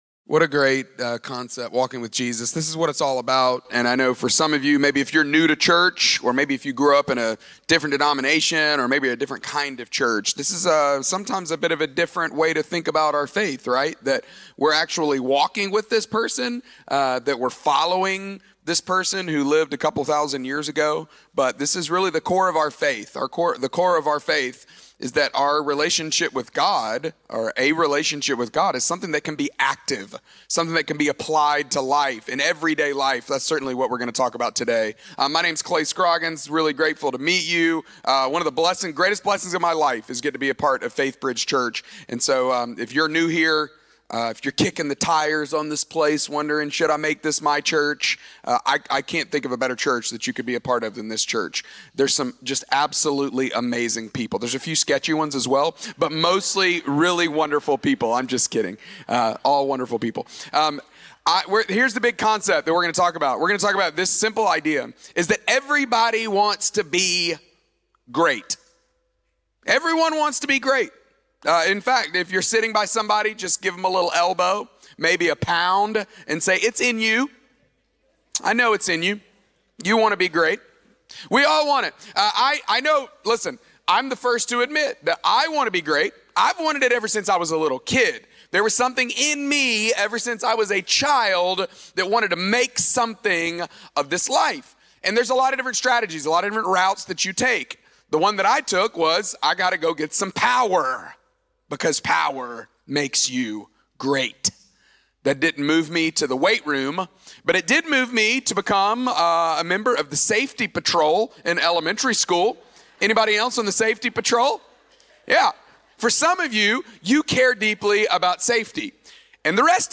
Faithbridge Sermons Desire to be Great Mar 22 2026 | 00:30:31 1x 00:00 / 00:30:31 Subscribe Share Apple Podcasts Spotify Overcast RSS Feed Share Link Embed